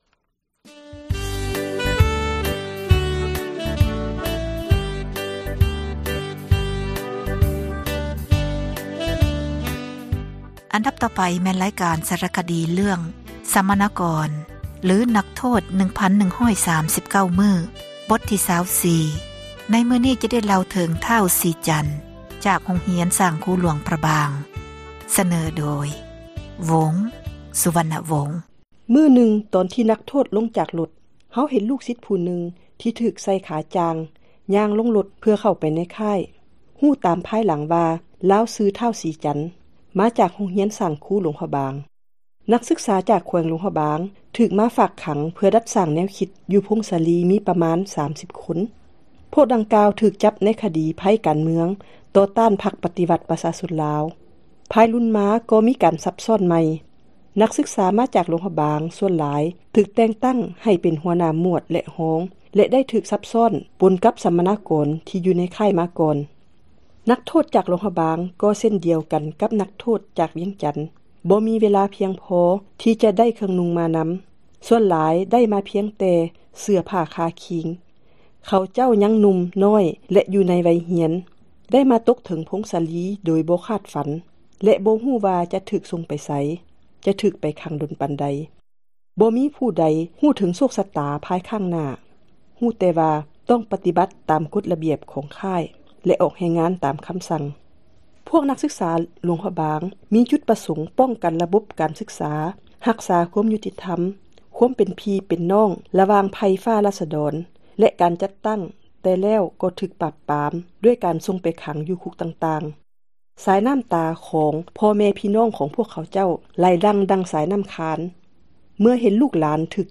ສາຣະຄະດີ ເຣື້ອງ ສັມມະນາກອນ ຫຼື ນັກໂທດ 1139 ມື້.